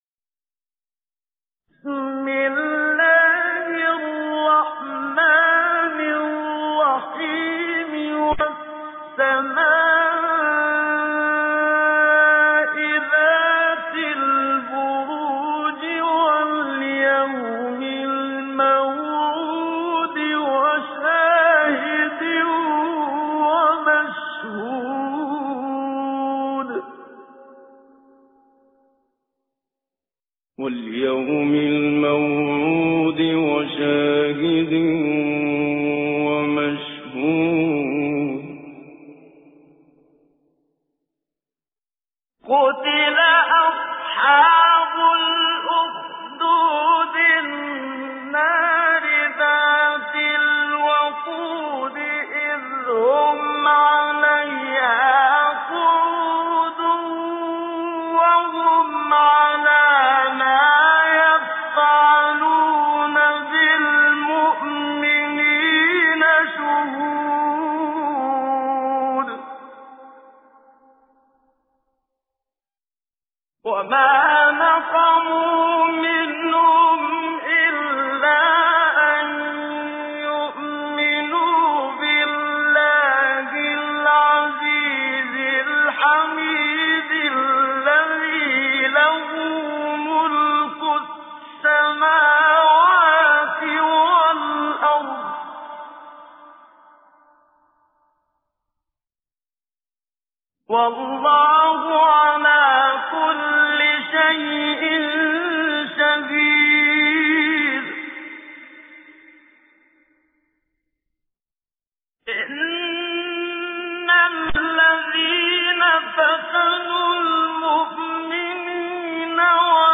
تجويد
سورة البروج الخطیب: المقريء الشيخ محمد صديق المنشاوي المدة الزمنية: 00:00:00